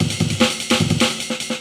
描述：这个破鼓有一种强烈的感觉。
Tag: 150 bpm Drum And Bass Loops Drum Loops 278.67 KB wav Key : Unknown